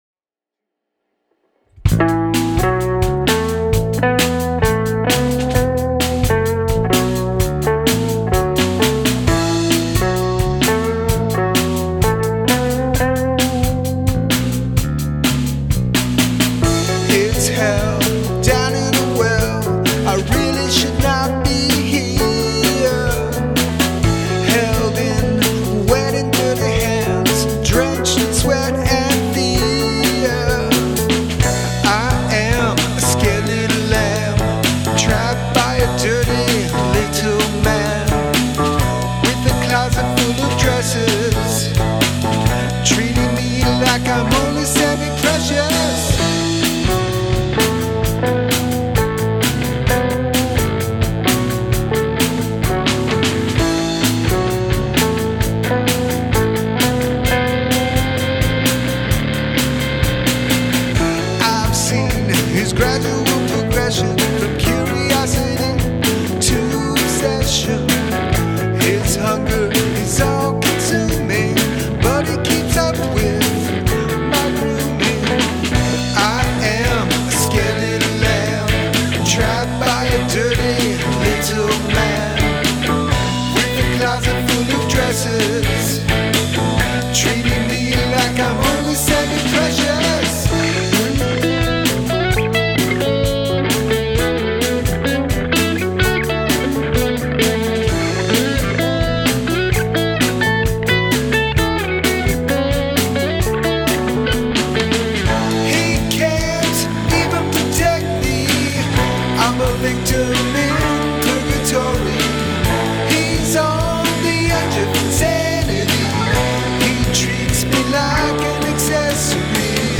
guitars, vocals
Bass tone is great, mind.